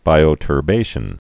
(bīō-tər-bāshən)